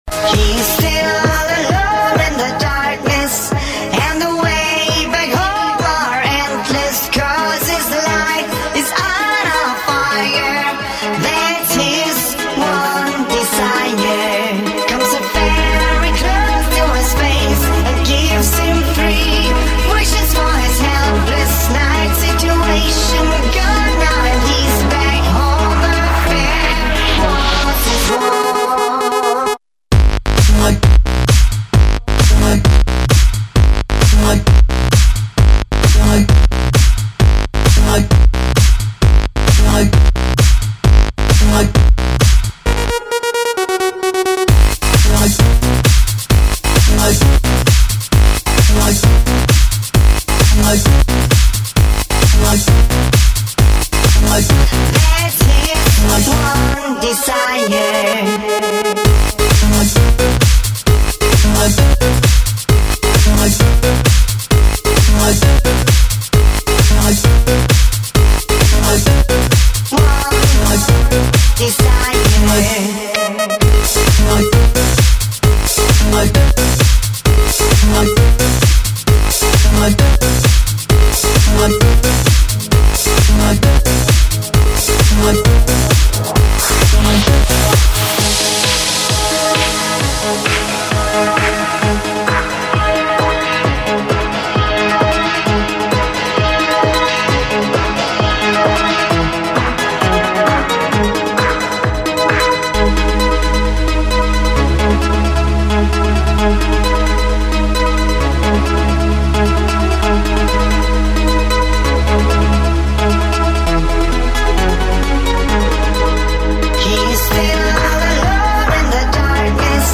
Жанр:Electro/House